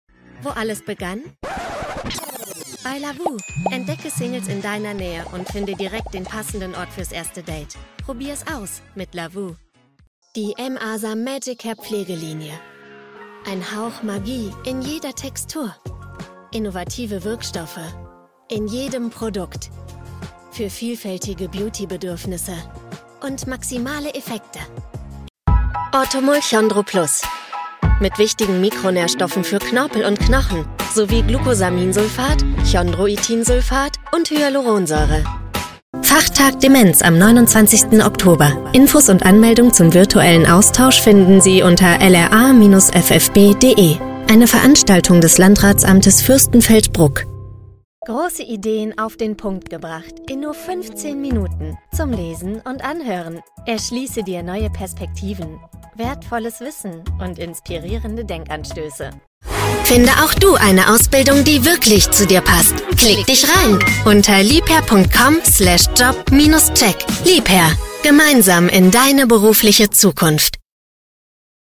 Female
Bright, Character, Cheeky, Confident, Cool, Corporate, Friendly, Natural, Soft, Warm, Versatile, Young, Approachable, Assured, Authoritative, Bubbly, Conversational, Energetic, Engaging, Funny, Posh, Reassuring, Sarcastic, Smooth, Streetwise, Upbeat
My fresh and young, yet warm voice is often described as special and trustworthy at the same time.
REEL COMMERCIAL DE.mp3
Microphone: Neumann TLM49